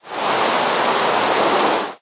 rain2.ogg